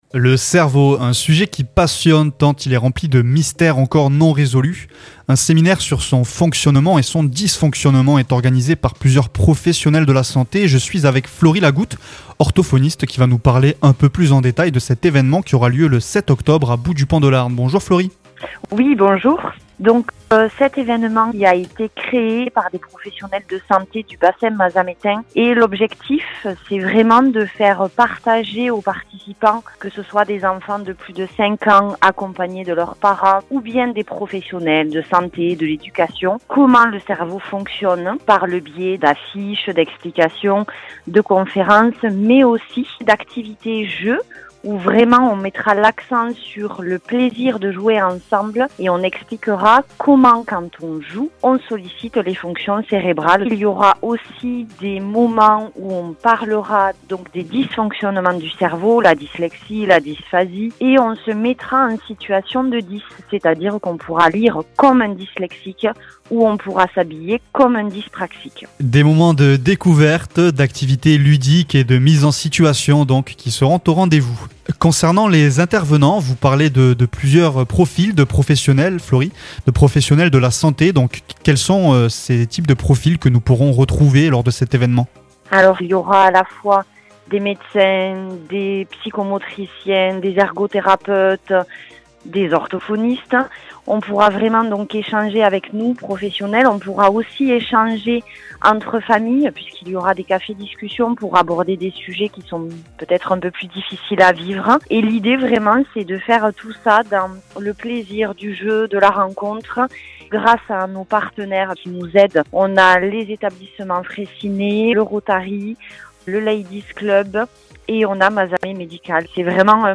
Conférence à Bout du pont de l'Arn